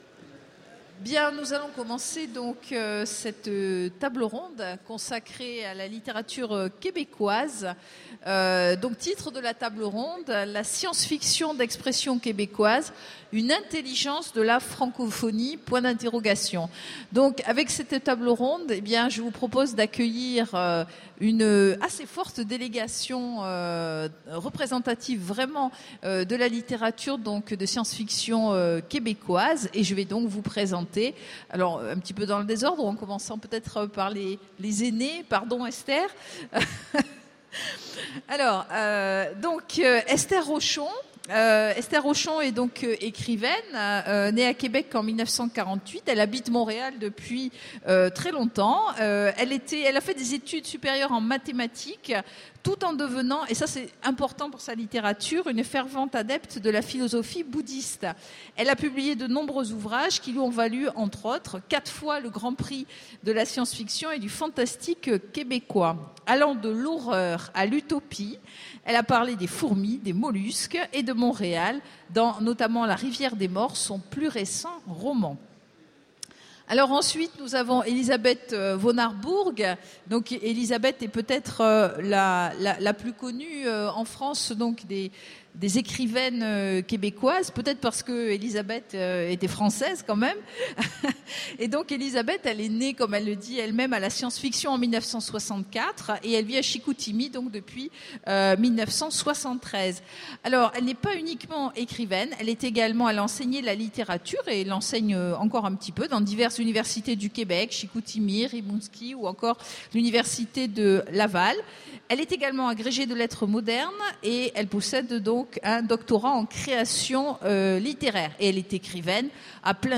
Mots-clés Québec SF Conférence Partager cet article